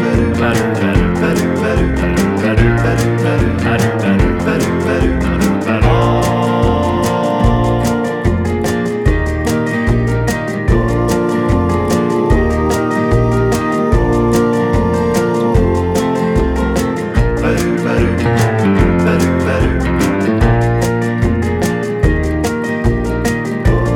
Country (Male)